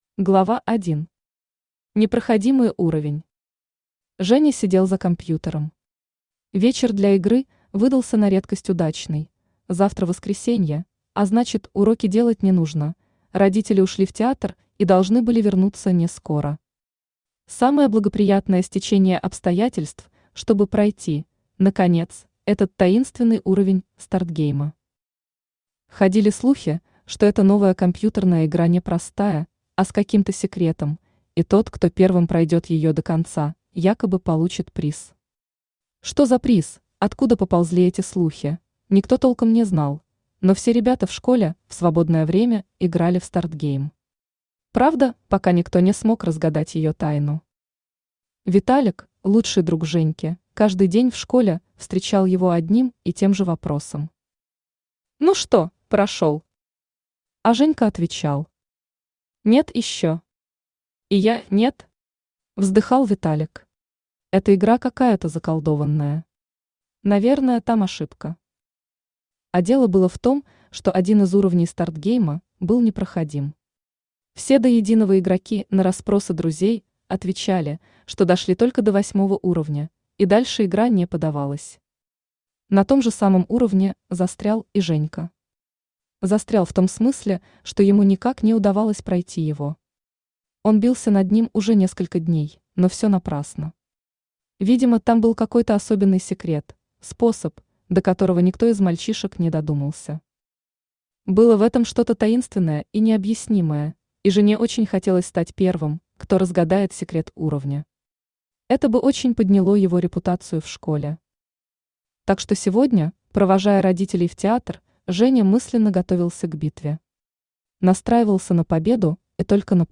Aудиокнига Стартгейм Автор Юлия Иванова Читает аудиокнигу Авточтец ЛитРес.